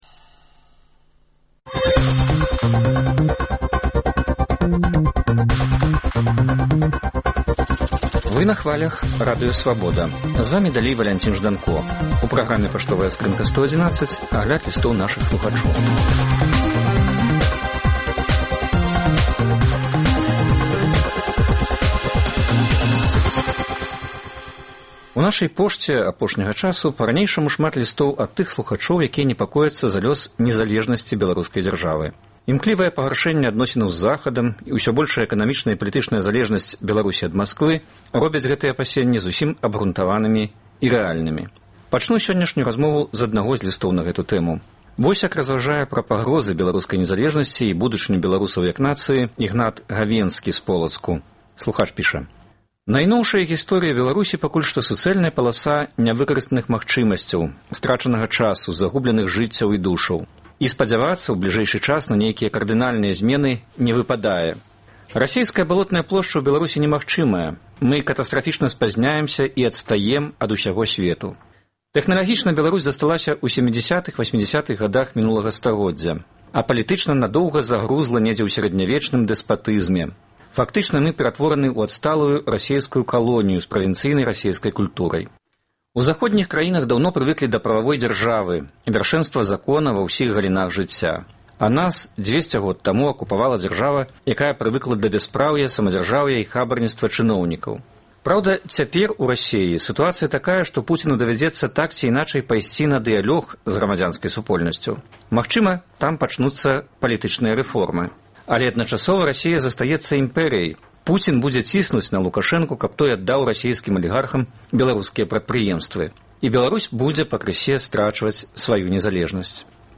Лісты на Свабоду чытае і камэнтуе